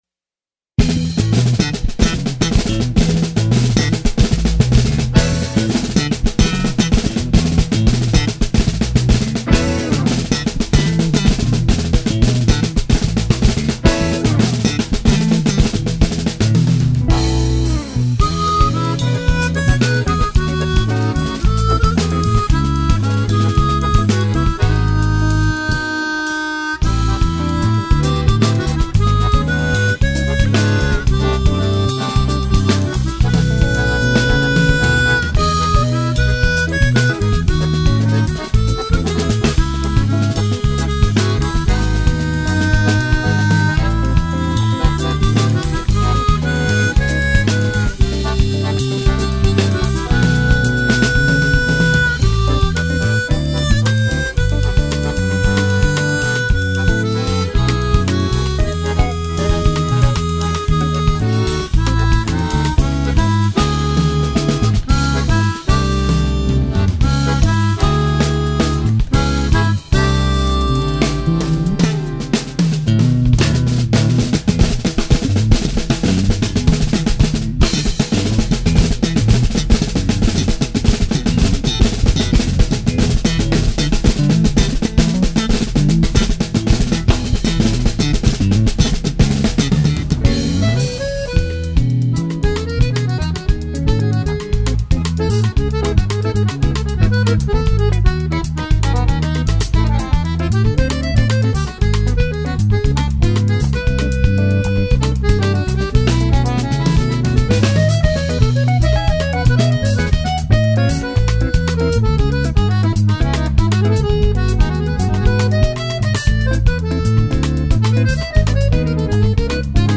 2809   04:01:00   Faixa: 7    Jazz
Bateria, Percussão
Baixo Elétrico 6, Violao Acústico 6
Guitarra, Viola
Acoordeon